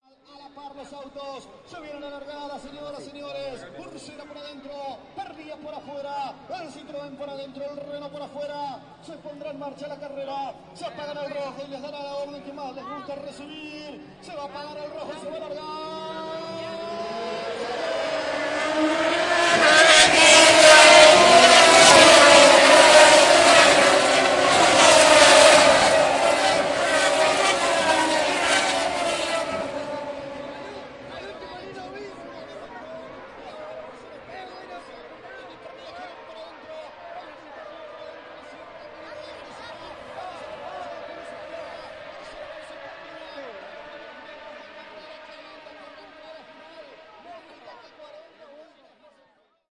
购物中心的氛围
描述：在阿根廷的购物中心。
Tag: 抖动 交谈 大厅 购物 环境 活动 背景 购买 ATMO 商场 说话的人 语气 中心 人群 房业 贸易 大气 城市